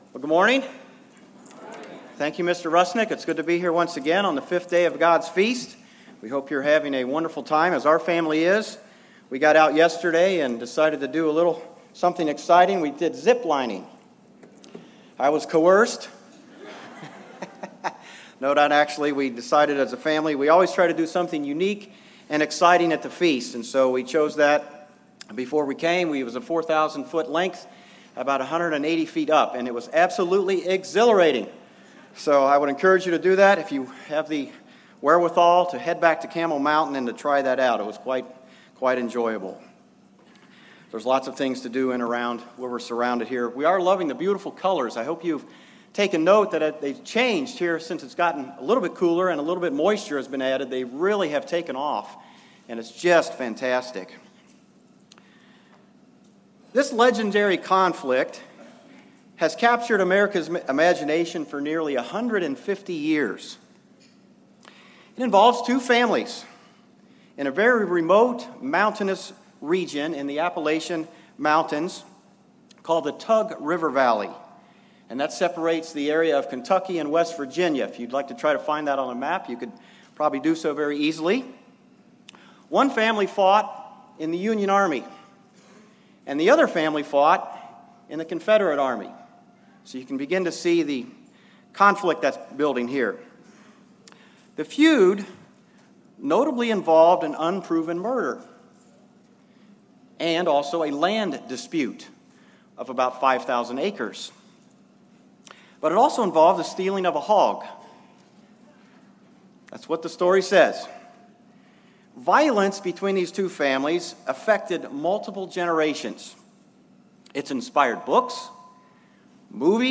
This sermon was given at the White Haven, Pennsylvania 2017 Feast site.